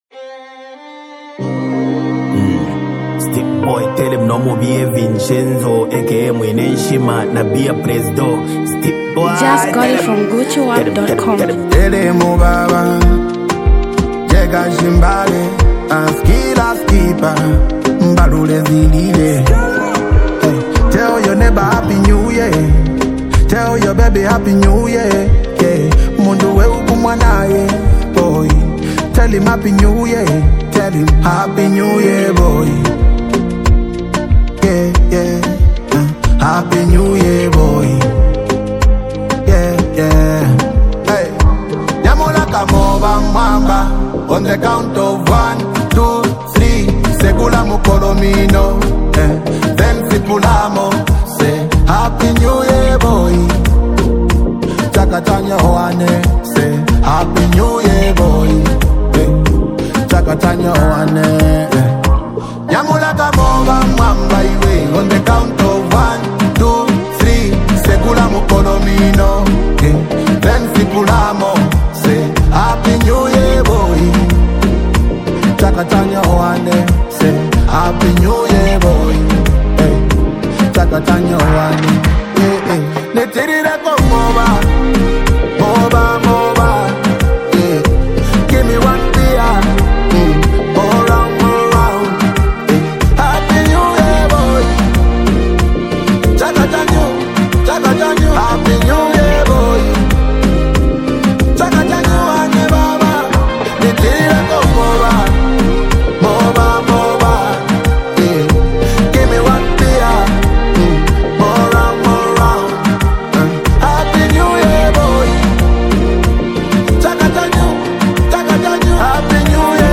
powerful melodic sound